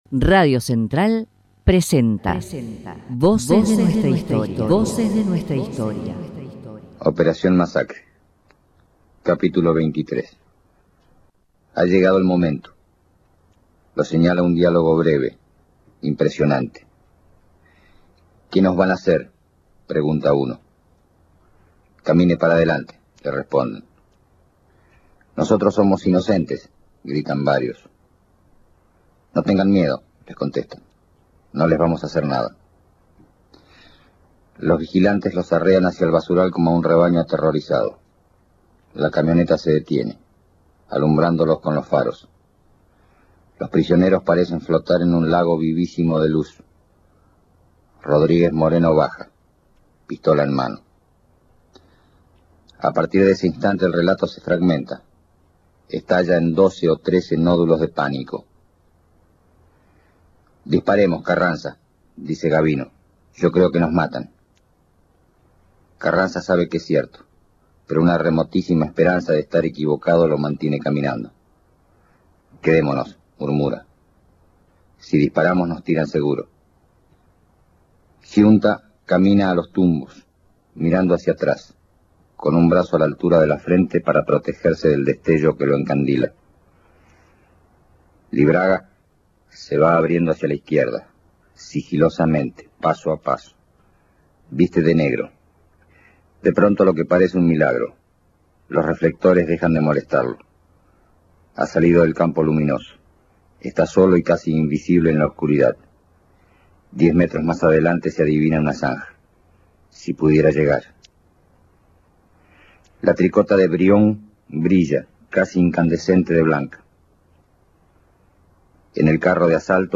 RODOLFO WALSH leyendo capítulo de OPERACIÓN MASACRE